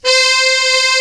Index of /90_sSampleCDs/Giga Samples Collection/Sax/HARD + SOFT
TENOR HARD.3.wav